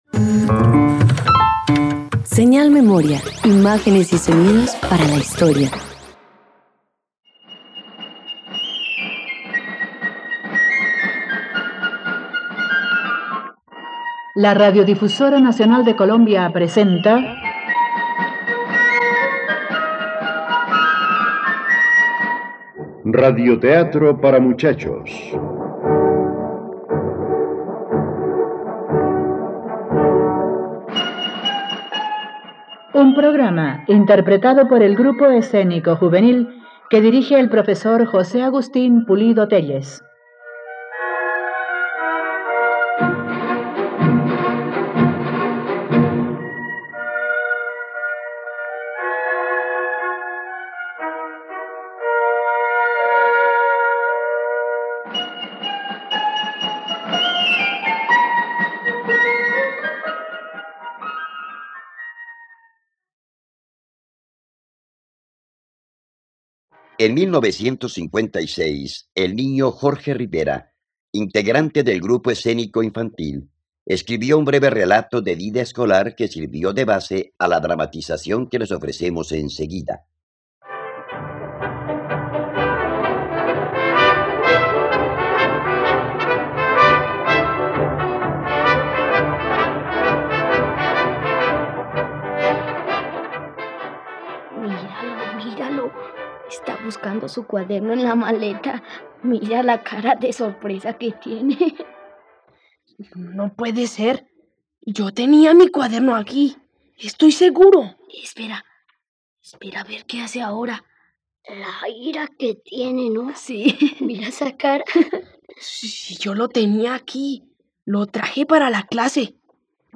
El primer puesto - Radioteatro dominical | RTVCPlay